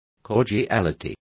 Complete with pronunciation of the translation of cordiality.